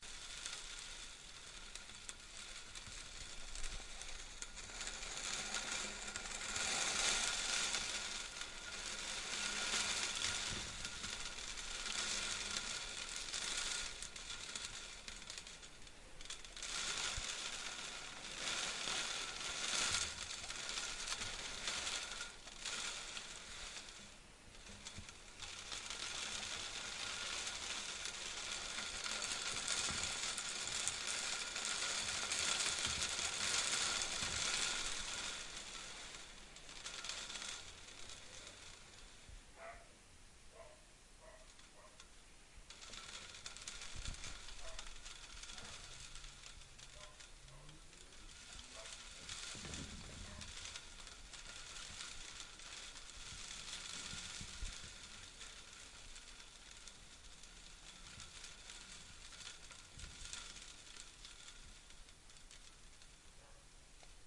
户外氛围日
描述：记录在南非郊区比勒陀利亚的一个炎热的下午。狗偶尔会吠叫。以立体声录制。
Tag: OWI 背景声 声景 气氛 环境 背景 ATMO 氛围 一般噪声 气氛